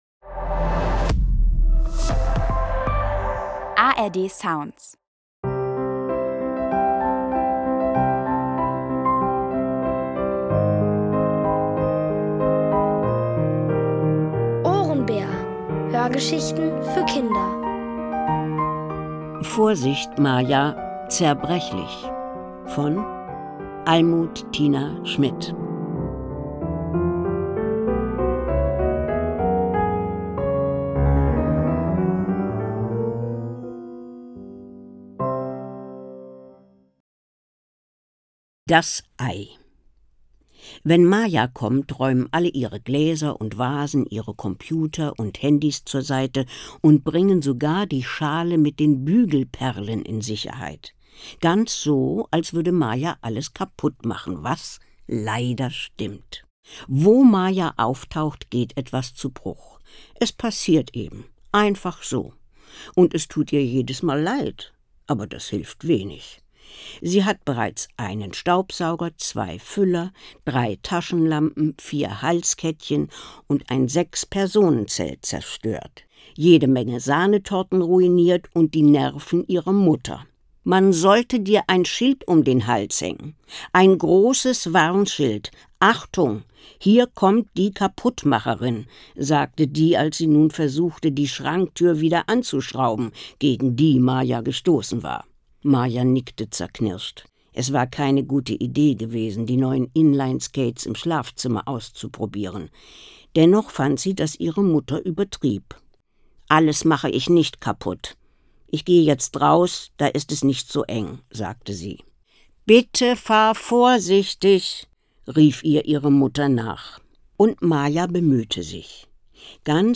Vorsicht, Maja, zerbrechlich! | Die komplette Hörgeschichte!